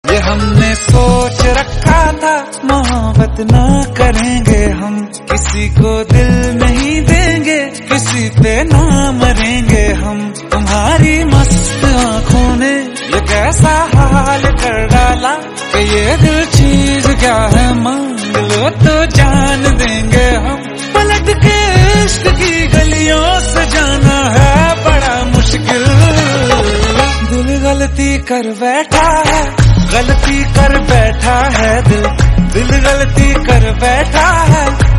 romantic